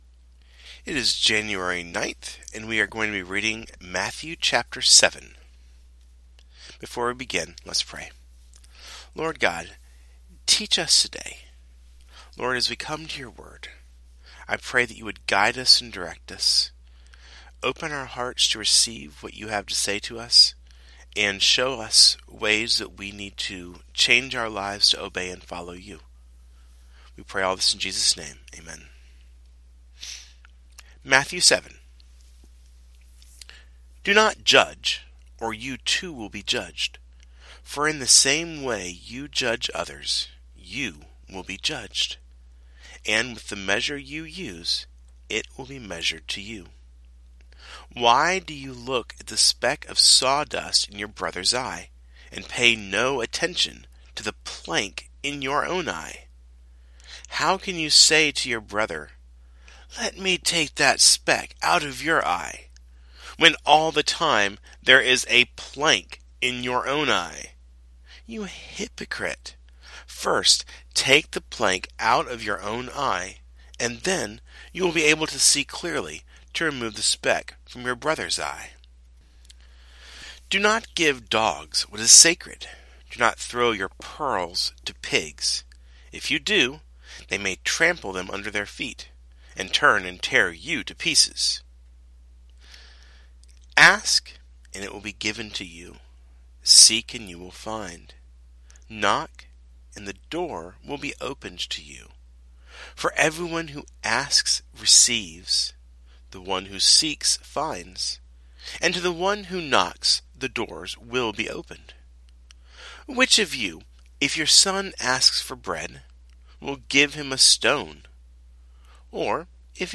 Today’s reading comes from Matthew 7.